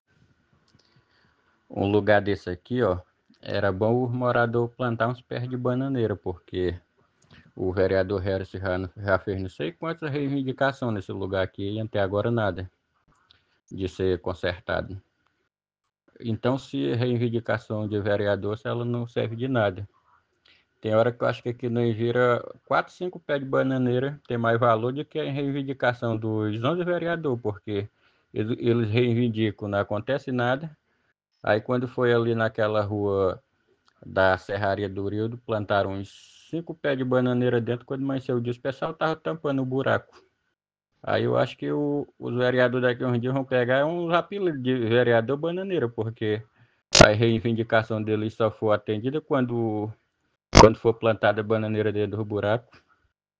Outro morador questiona a falta de respostas do poder público, mesmo diante das reiteradas cobranças feitas pelo Legislativo municipal: